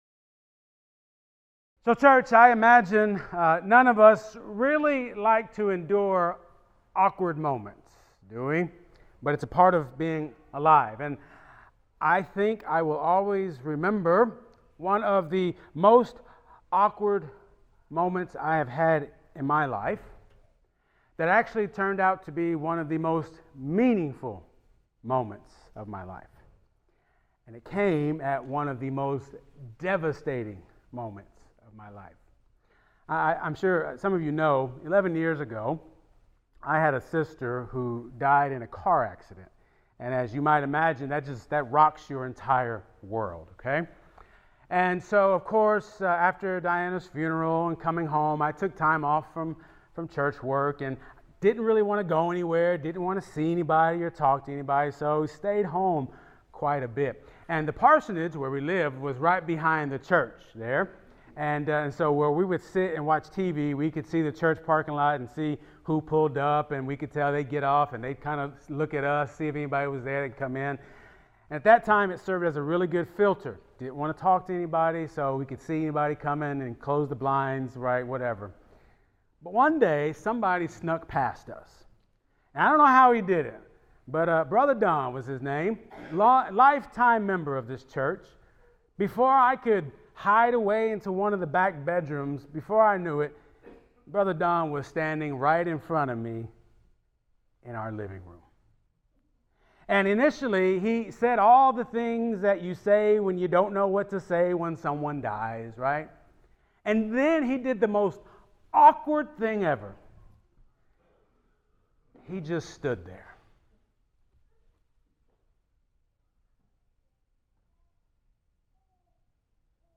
Sermons | Kelsey Memorial UMC